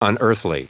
Prononciation du mot unearthly en anglais (fichier audio)
Prononciation du mot : unearthly